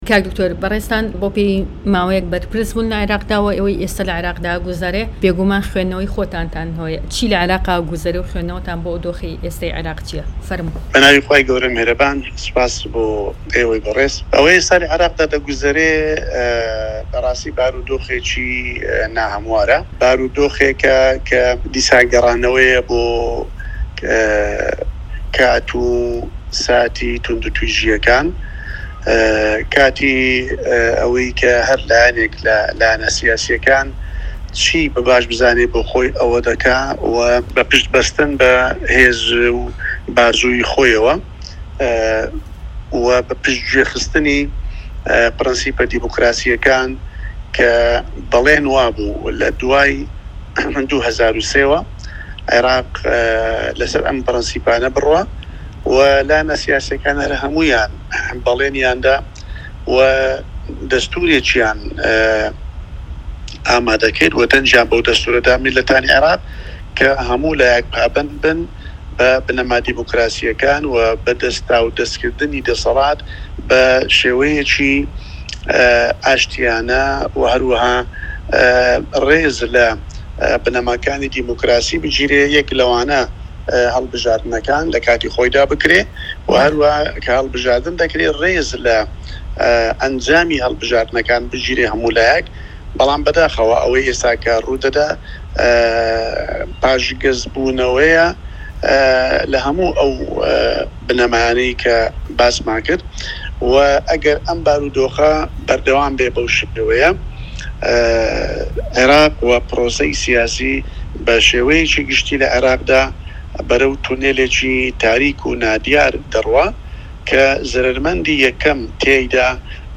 دەقی وتووێژەکەی دکتۆر بەشیر حەداد